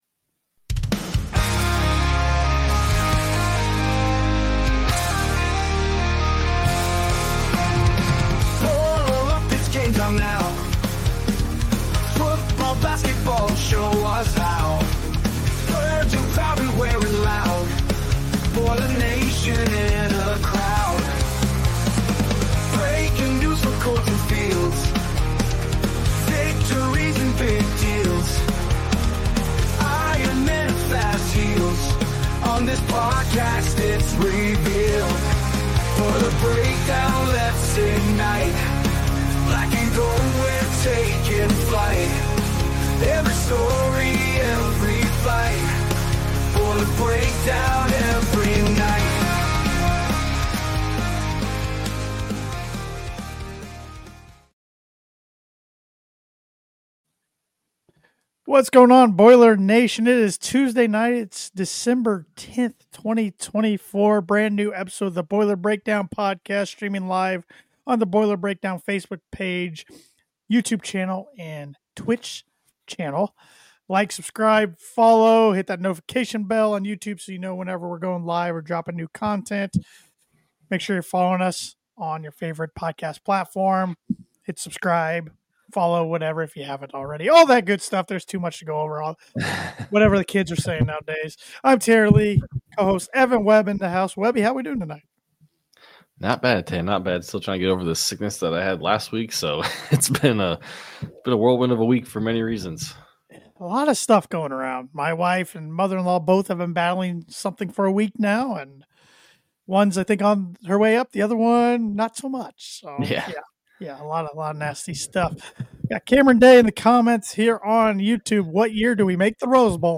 The Boiler Breakdown is a weekly podcast hosted by three lifelong Purdue fans who breakdown everything related to Purdue Football and Men's Basketball.